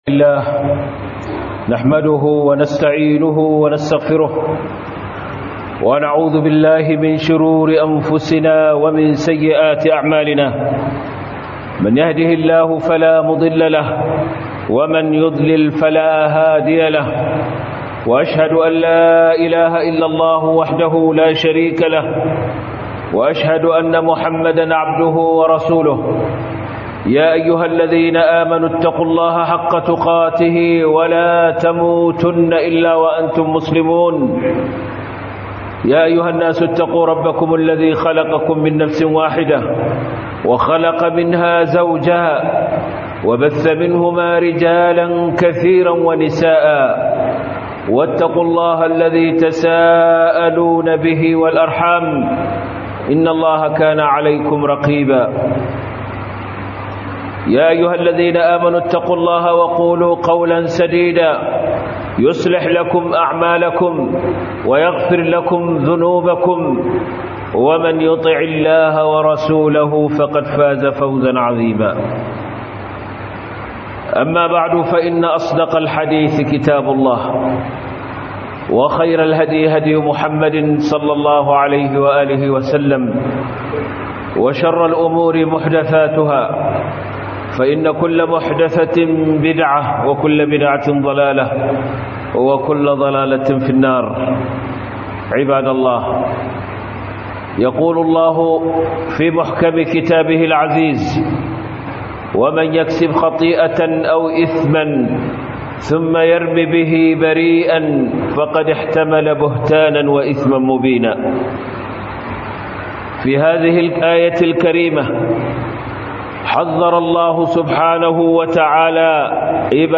Hadarin yi wa Musulmi Kage - HUDUBA